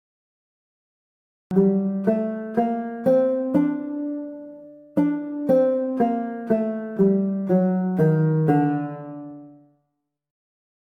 Arabic-scale_hijaz.mp3